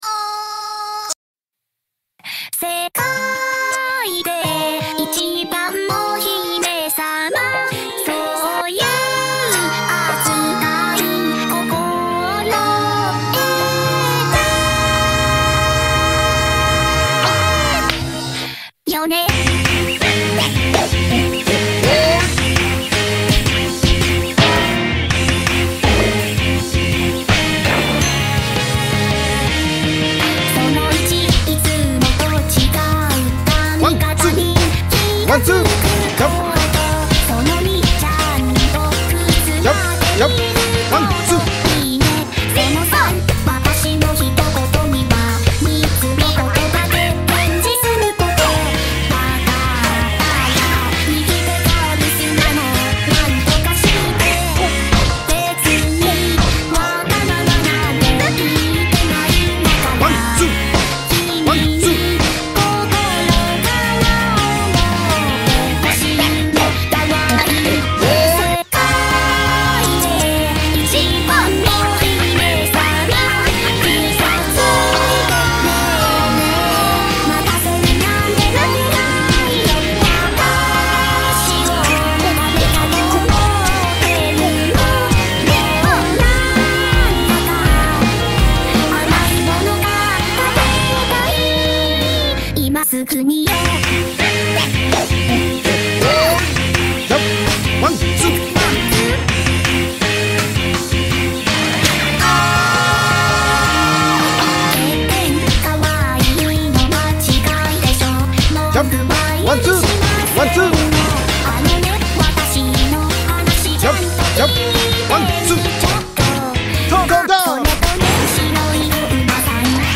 BPM165-165
Audio QualityPerfect (High Quality)
Full Length Song (not arcade length cut)